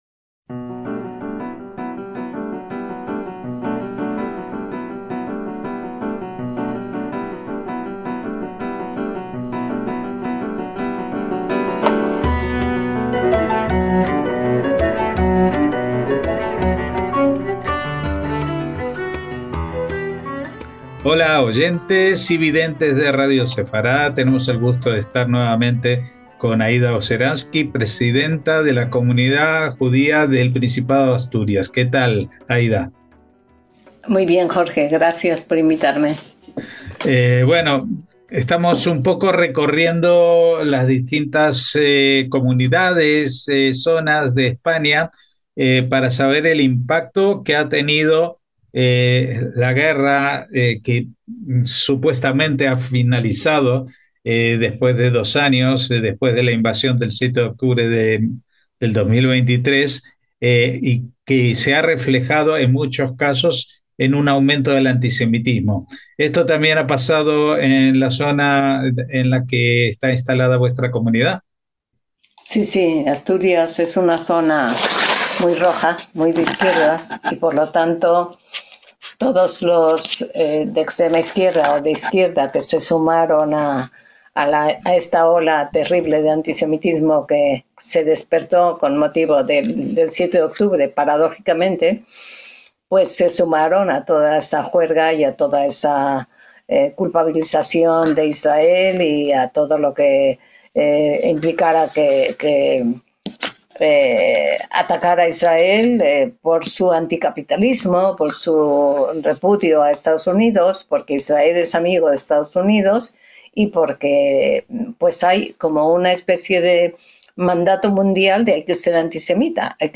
A FONDO - En este entrevista a fondo